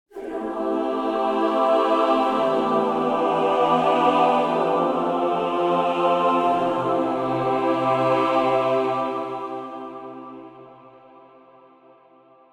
Reverieの最大の特徴は、「ブダペスト・スコアリング・クワイア」と共に録音されていることです。
美しいクワイアサウンドから実験的な音響表現まで、その多彩な表現力を実際にいくつかのプリセットで聴いてみてください。
このように、神秘的な合唱の美しさに加えて、CUBEならではの実験的な加工が施されたプリセットも収録されています。